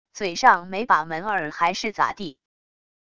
嘴上没把门儿还是咋地wav音频生成系统WAV Audio Player